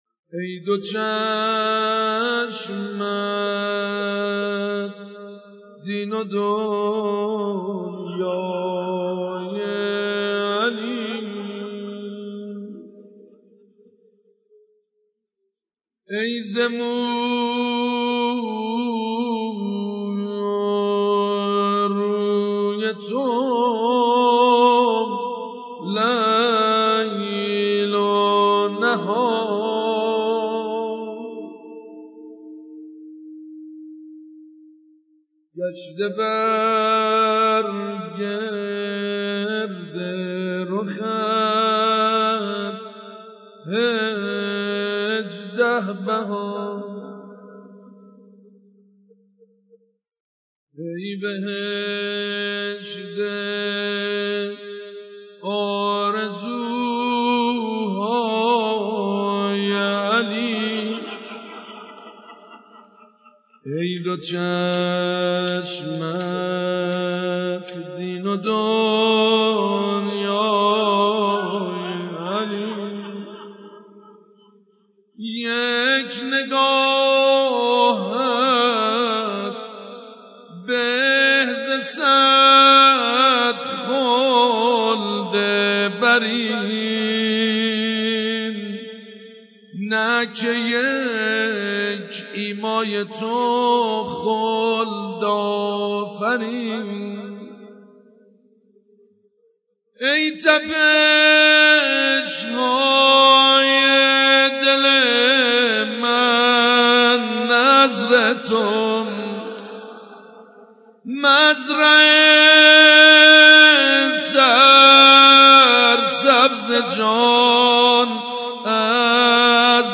ذکر مدح بانوی پهلو شکسته، حضرت زهرا(س